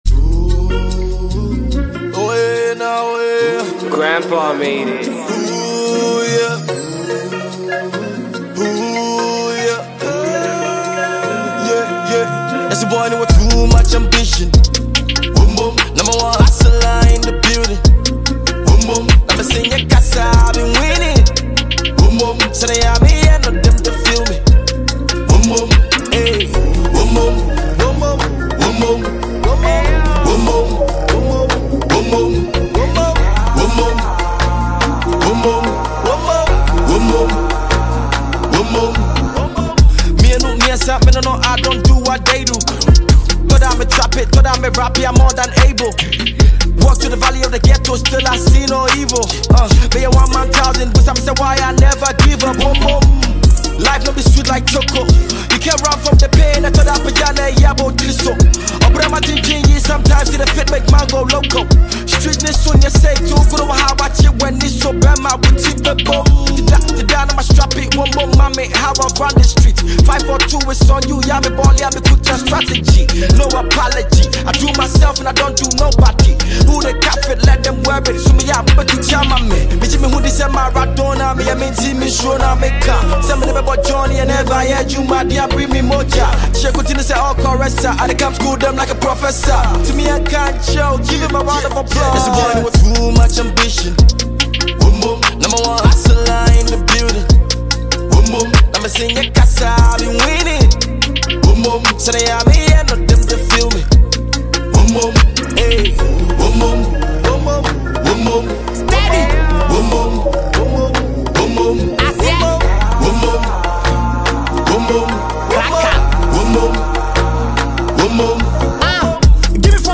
Ghanaian rapper and singer